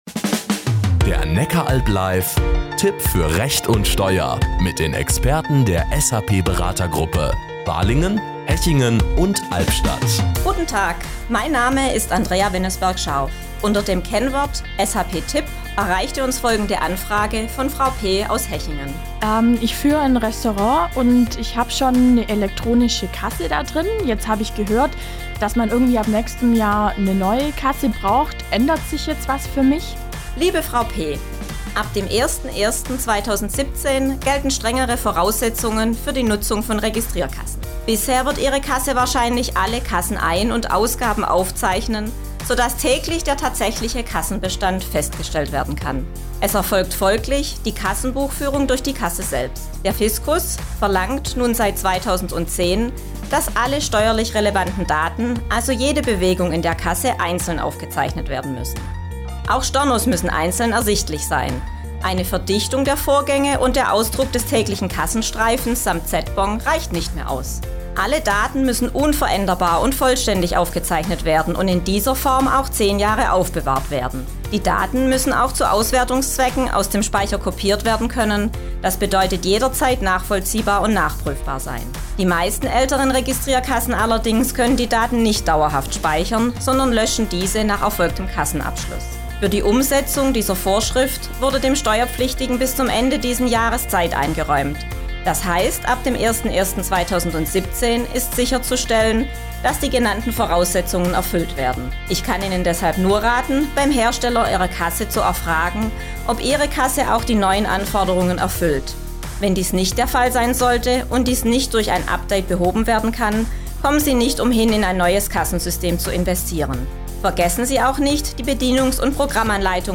Radiospots